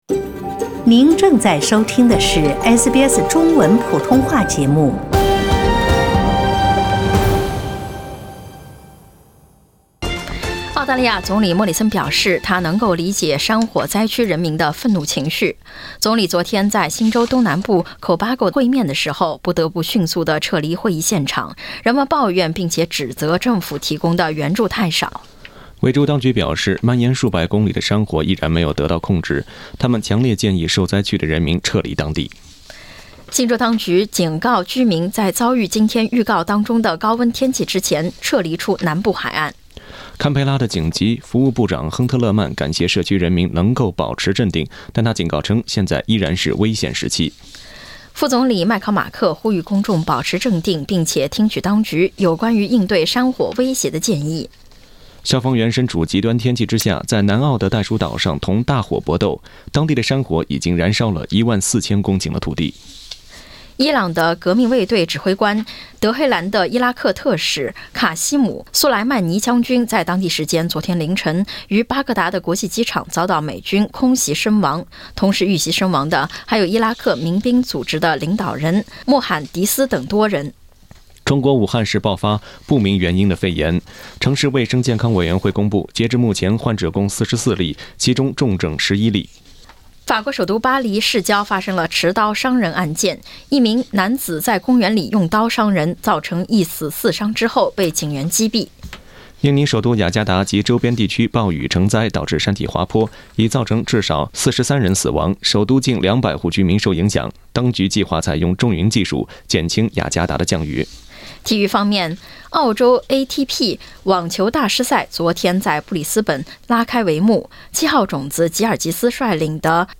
SBS早新闻（1月4日）